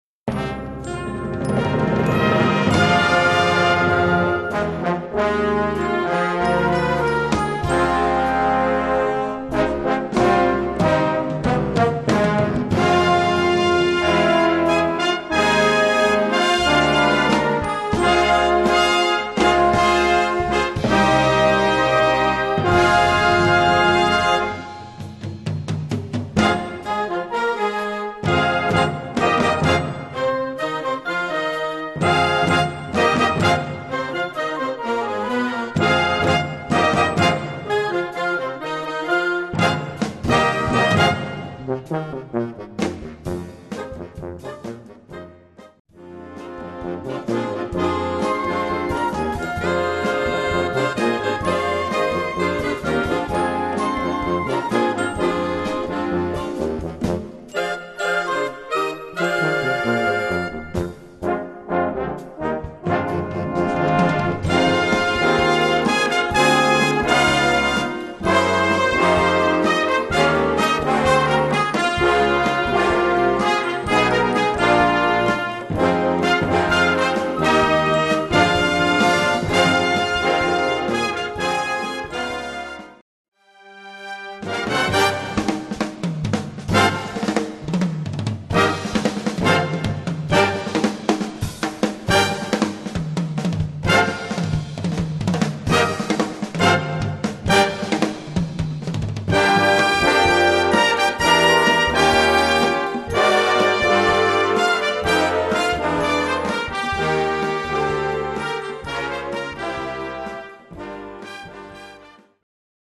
Gattung: Spiritual
Besetzung: Blasorchester
in eine Jazz-Rock-Fassung für Blasorchester